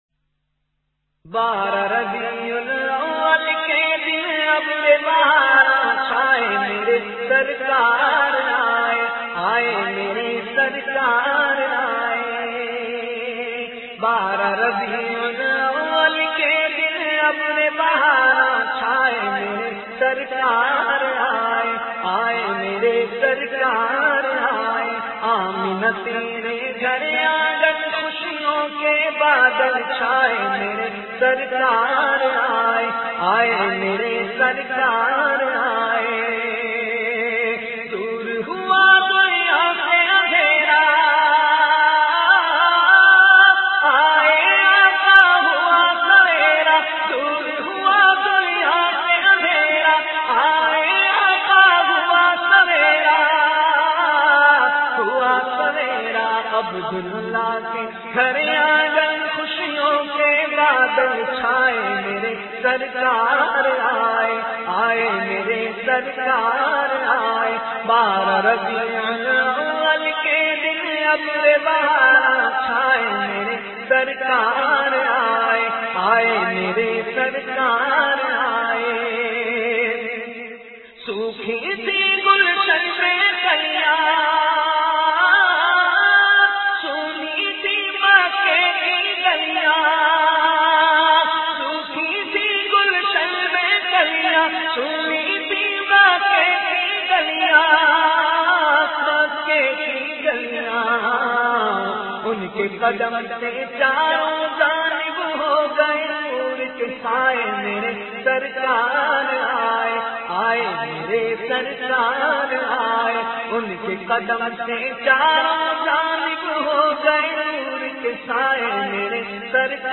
urdu naats